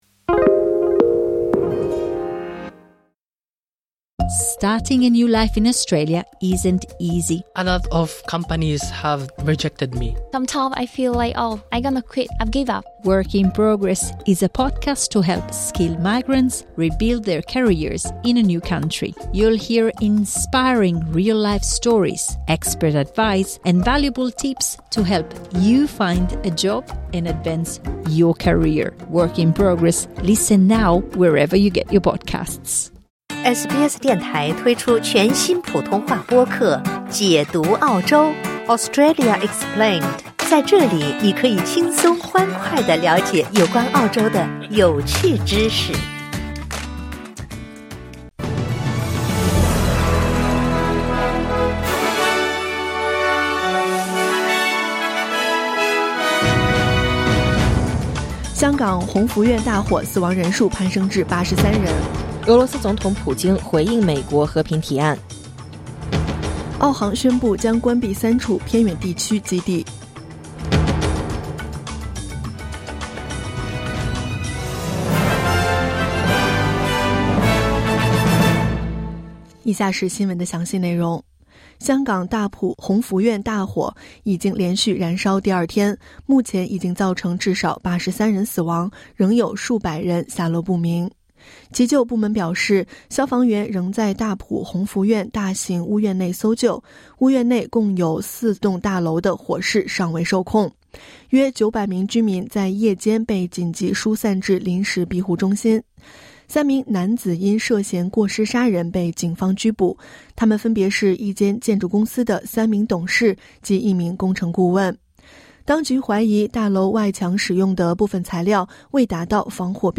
SBS Mandarin morning news Source: Getty / Getty Images